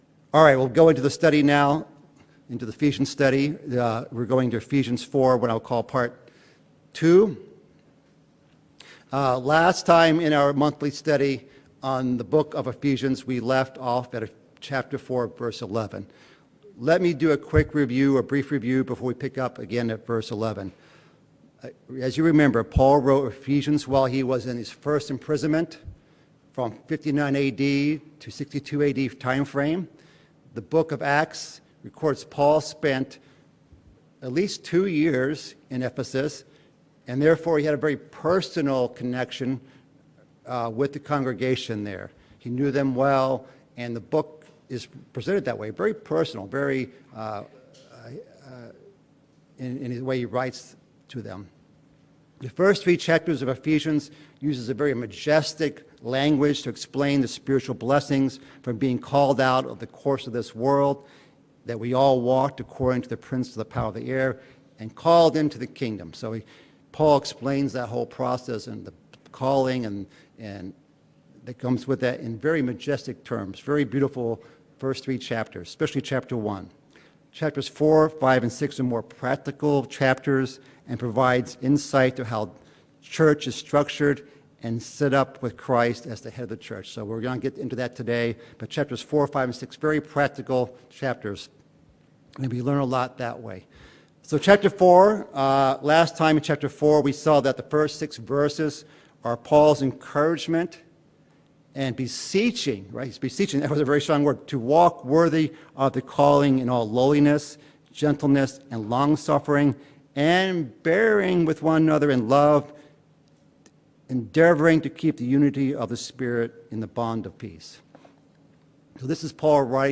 Bible Study: Ephesians
This study, given in Chicago and virtually given in NW Indiana and Beloit, WI, covers verses 11-16 (approximately) of Ephesians 4.